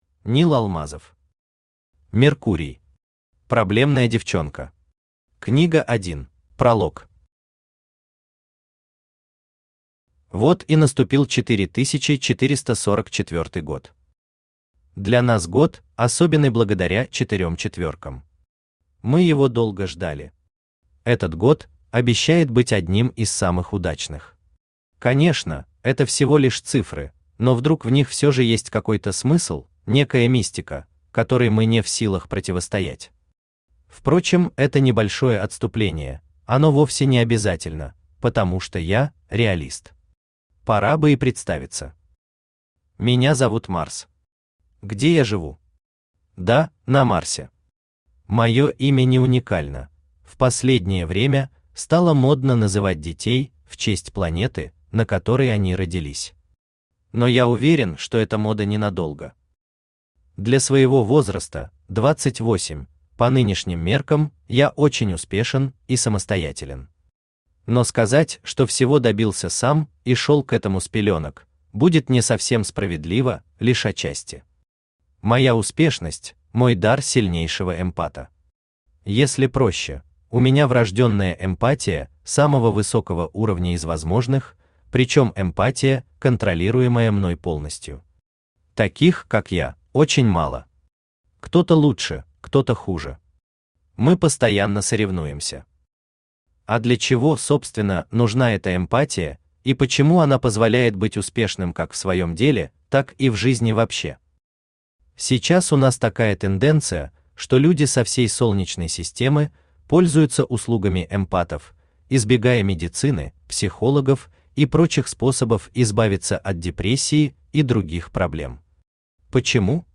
Аудиокнига Меркурий. Проблемная девчонка. Книга 1 | Библиотека аудиокниг
Книга 1 Автор Нил Алмазов Читает аудиокнигу Авточтец ЛитРес.